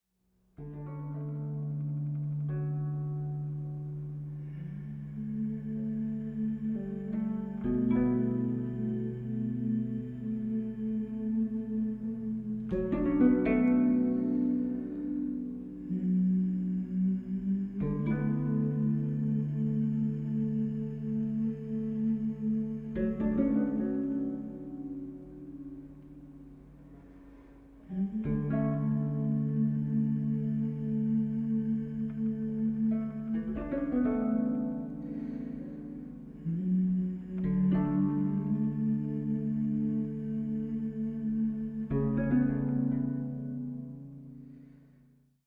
十七絃箏と、カリンバ／ハーモニウム／女声による音の綴り。
(17-strings koto)
voice, harmonium